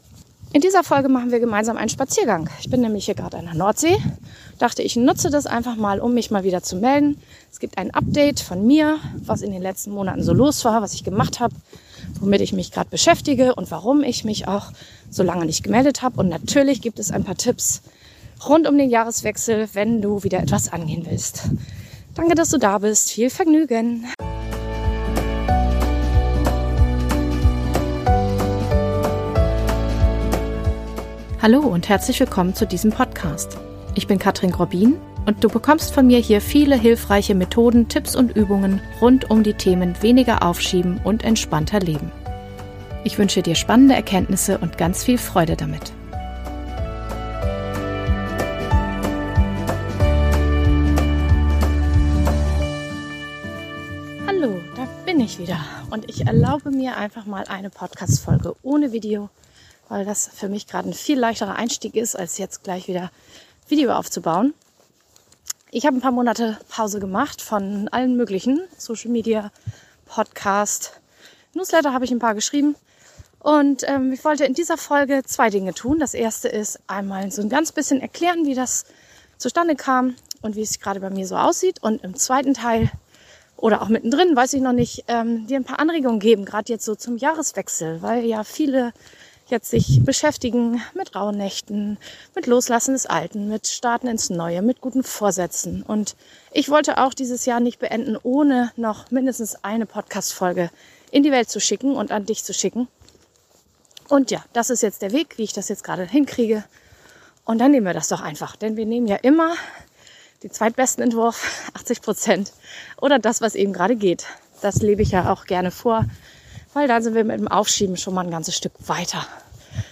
Heute machen wir einen gemeinsamen Spaziergang in der Nähe der Nordsee und ich berichte dir, wie mein restliches Jahr 2025 verlaufen ist seit der letzte Episode und warum ich so eine lange Pause gemacht habe.